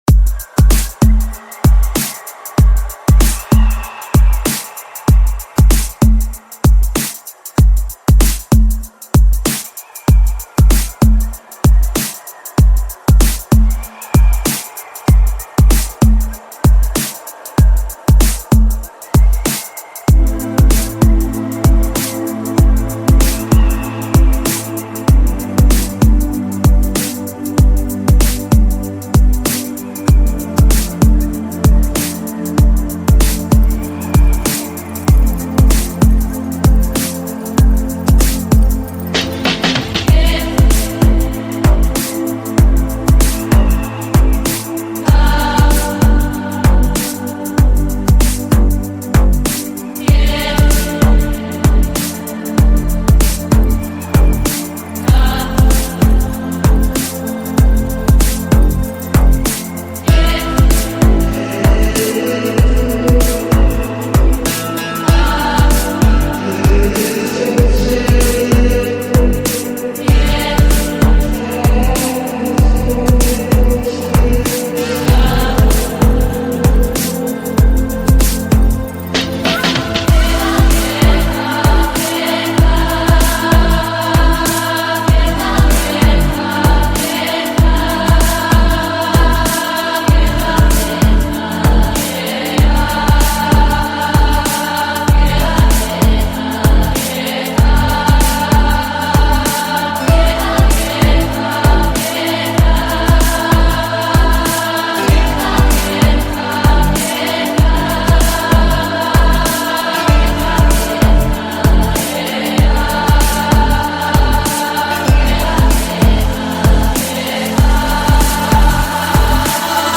• Жанр: Trance